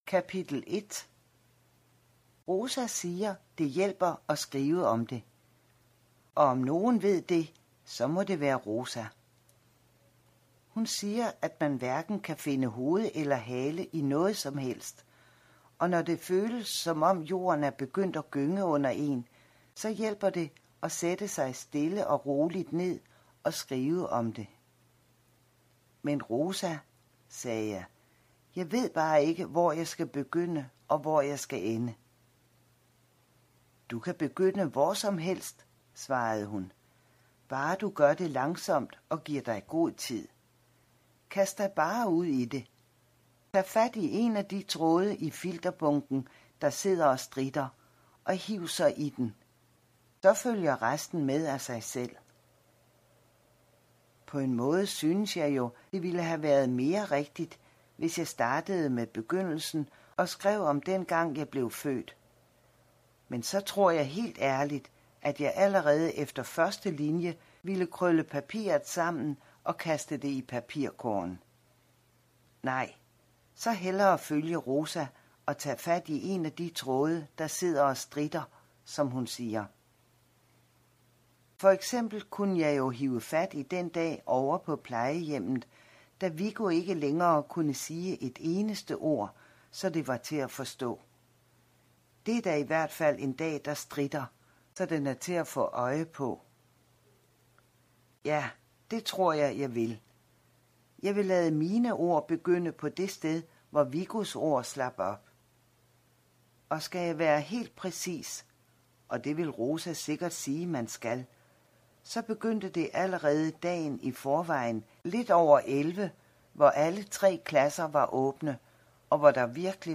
Hør et uddrag af Nøglen til Cecilie Nøglen til Cecilie Format MP3 Forfatter Bodil Sangill Bog Lydbog E-bog 74,95 kr.